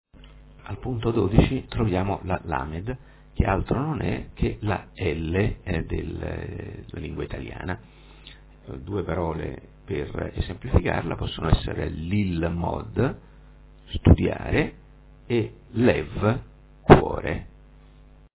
Nell'ebraico contemporaneo si pronuncia "b" la bet con il dagesh בּ, mentre la bet senza dagesh ב si pronuncia "v".